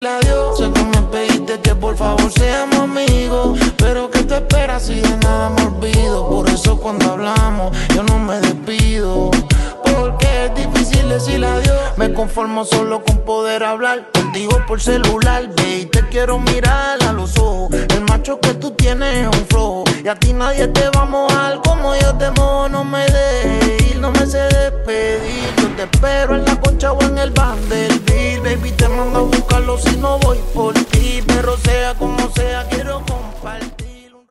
música Pop